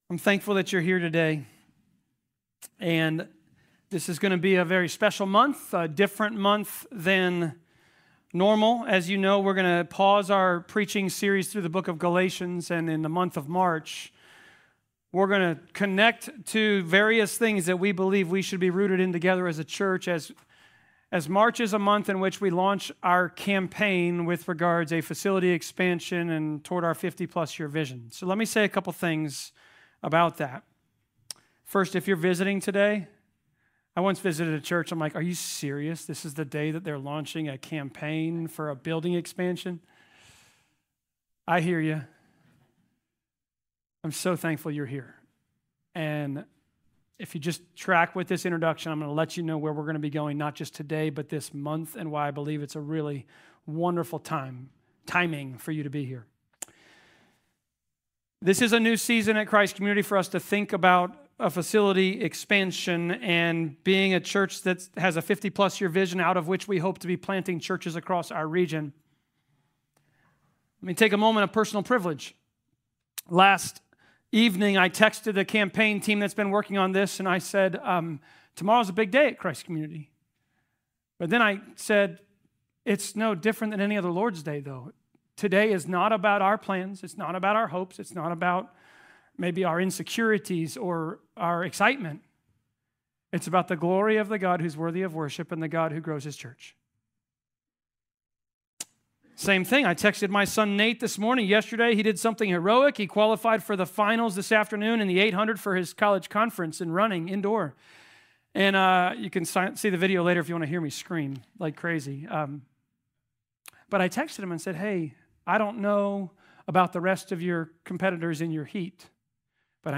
Passage: Acts 8.26-40 Service Type: Sermons « DUAL-DEPENDENCY IS NON-DEPENDENCY.